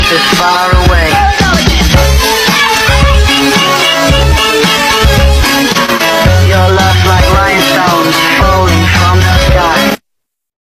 ха ха ха.